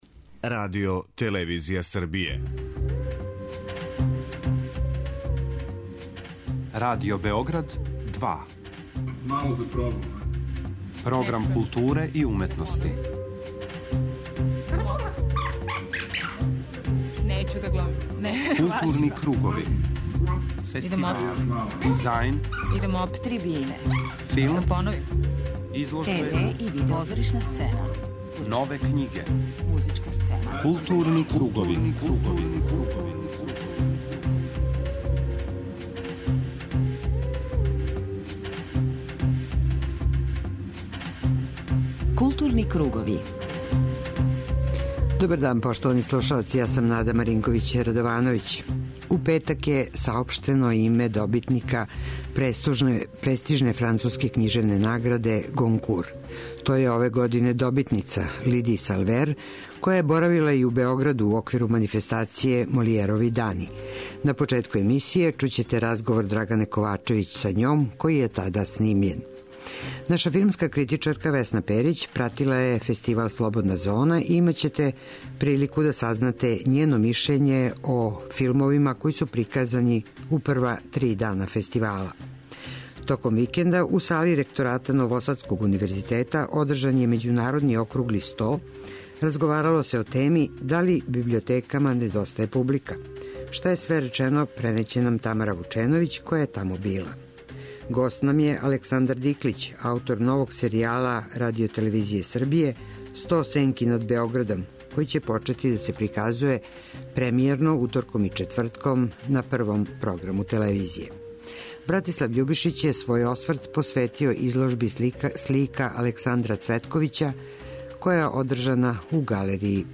Објавићемо разговор са Лиди Салвер, овогодишњом добитницом највећег француског књижевног признања "Гонкур". Овај снимак забележен је за време њеног боравка у Београду, током манифестације Молијерови дани.